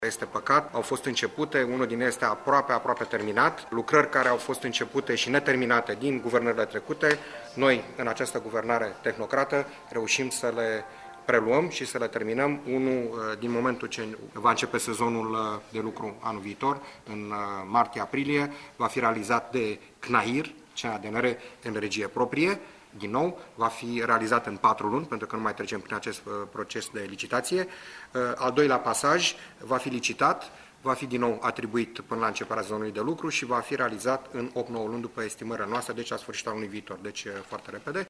Unul dintre obiective va fi realizat în regie proprie şi va fi gata în patru luni, iar pentru celălalt va fi organizată licitaţie, a anunţat astăzi la Arad, ministrul transporturilor, Sorin Buşe.